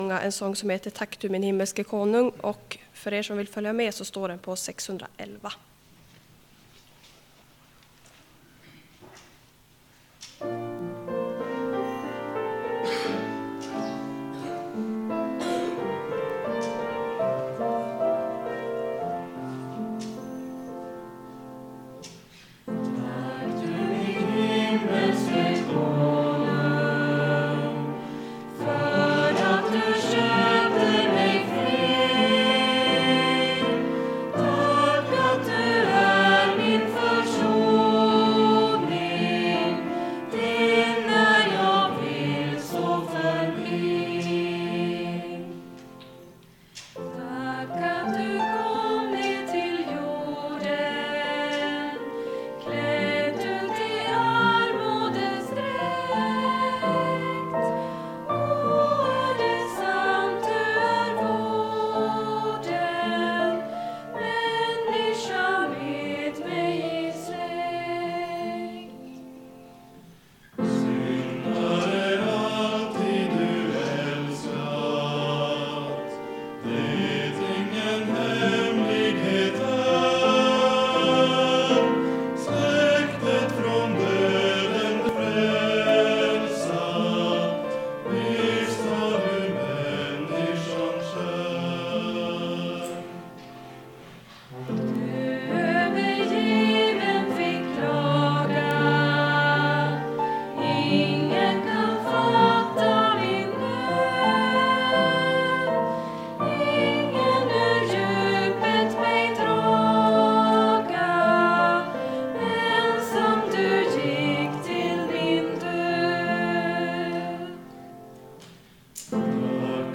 Lyssna till körsång – nytt från midsommar
Körsånger från Midsommarläger 2025, Ytterstfors Kursgård.